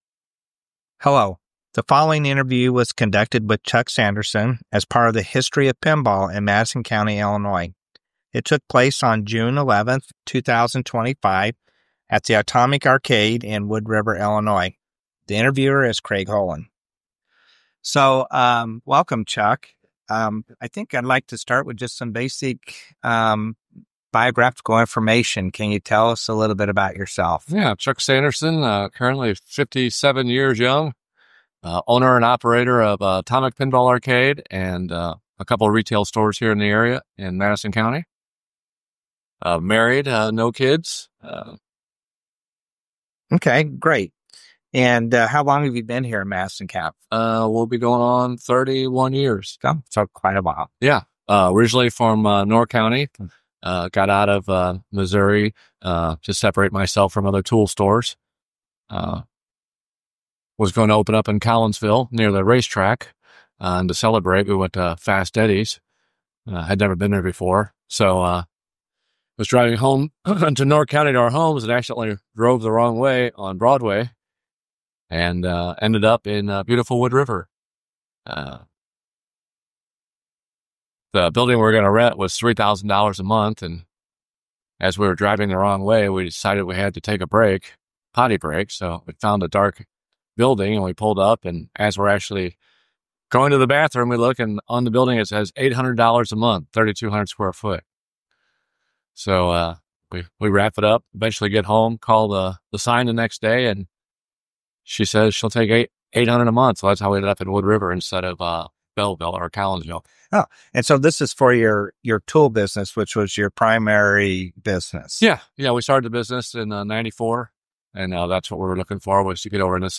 Oral History
Atomic Arcade, Wood River, Illinois